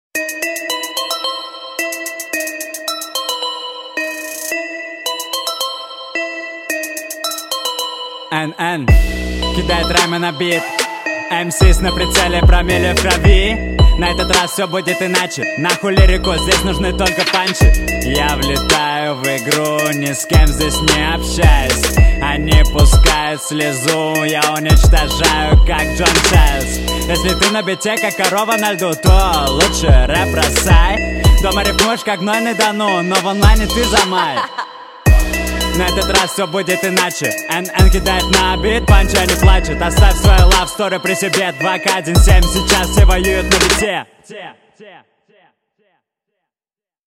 Немелодично напеваешь, в целом кривишь голос и сам себе ухудшаешь чисоту произношения